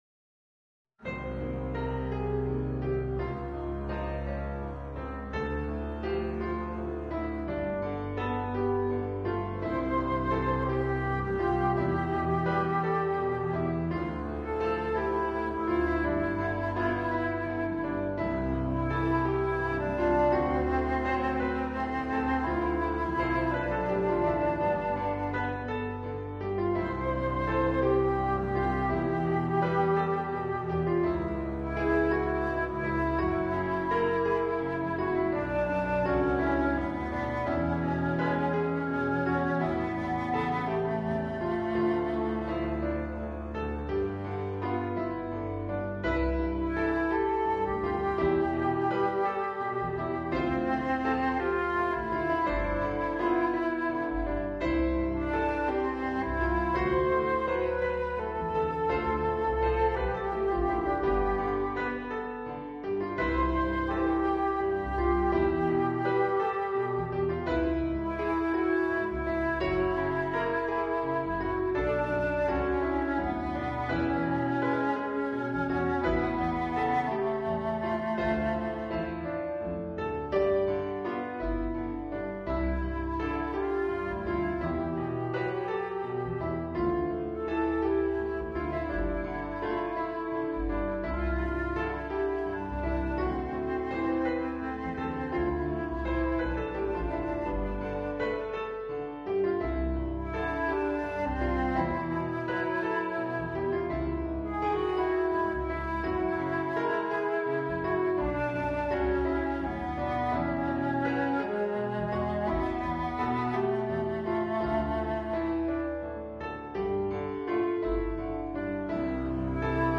Sönglag við kvæði Stefáns Ólafssonar (1619-1688), forföður míns af kyni Austfjarðaskálda.
frumfluttu lagið sem aukalag á tónleikum í Hörpu